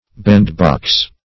Bandbox \Band"box`\ (b[a^]nd"b[o^]ks`), n.